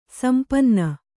♪ sampanna